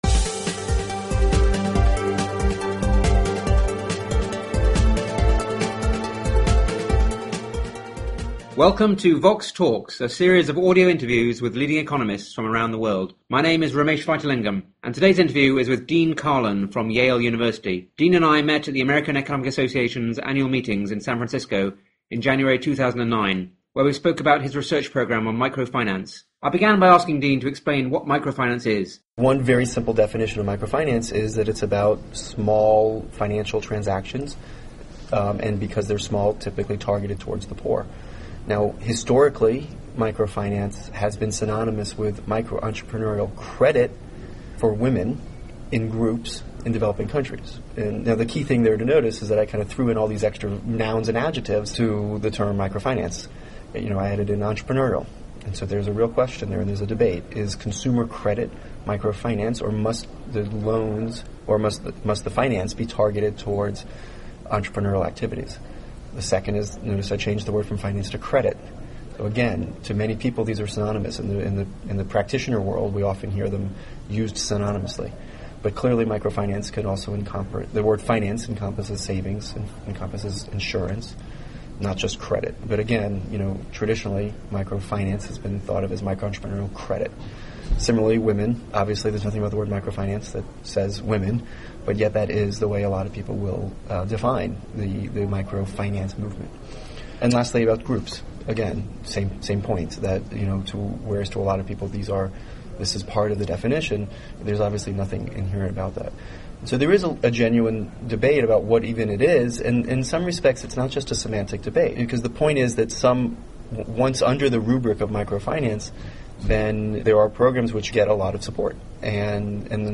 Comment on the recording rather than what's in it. They also discuss his work on ‘commitment contracts’, which people can use to try to modify their own behaviour. The interview was recorded at the American Economic Association meetings in San Francisco in January 2009.